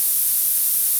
Turbo.WAV